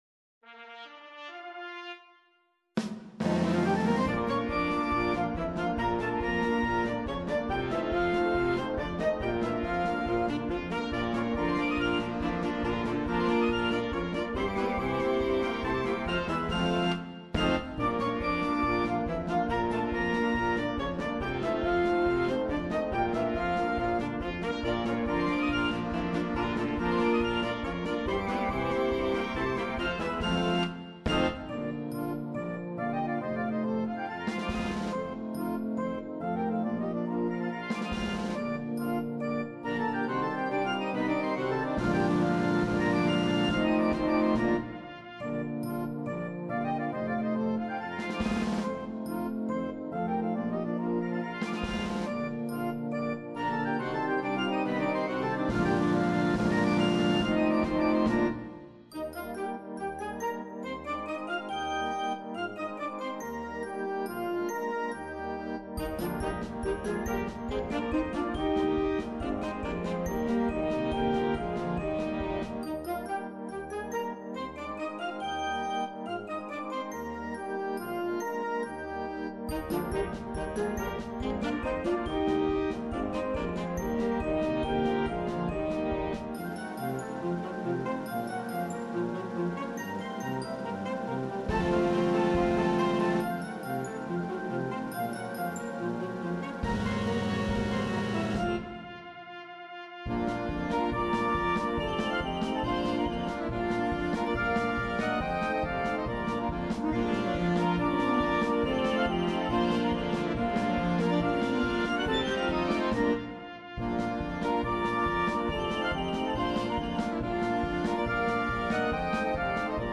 welches mit einigen Effekten überrascht.